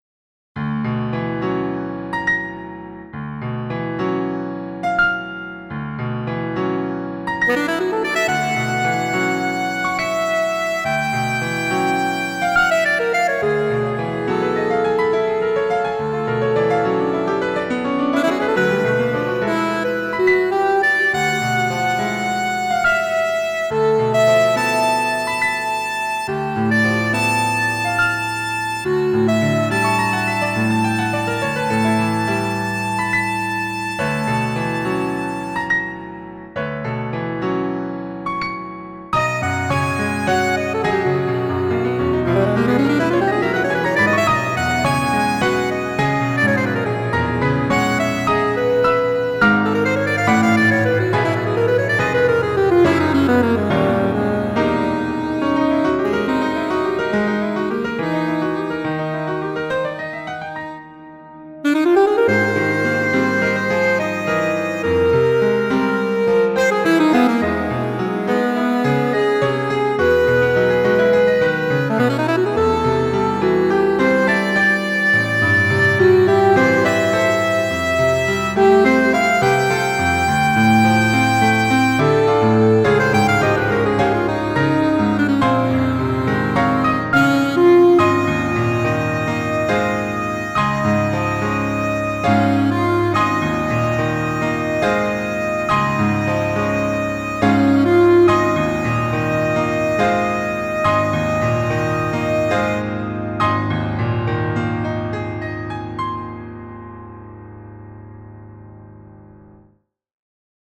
цикл пьес для саксофона-альта и фортепиано